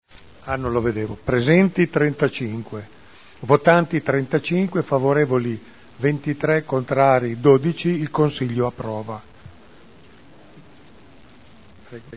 Giancarlo Pellacani — Sito Audio Consiglio Comunale
Seduta del 24/01/2011. Mette ai voti la delibera su approvazione modifiche allo Statuto di Hera S.p.A. (Commissione consiliare del 13 gennaio 2011).